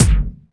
Index of /90_sSampleCDs/Roland L-CDX-01/KIK_Electronic/KIK_Analog K1
KIK BOING09R.wav